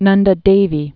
(nŭndə dāvē)